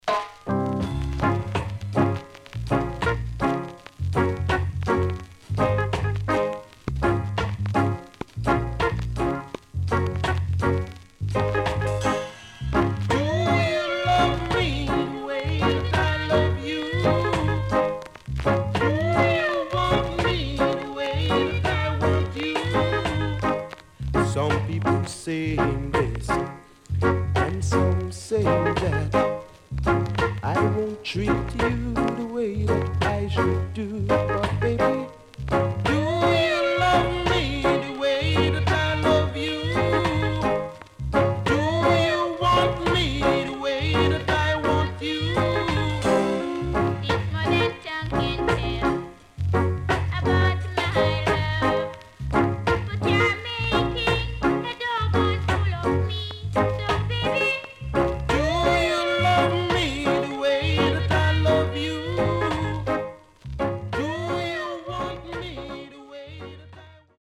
HOME > SKA / ROCKSTEADY  >  ROCKSTEADY
SIDE A:所々チリノイズ、プチノイズ入ります。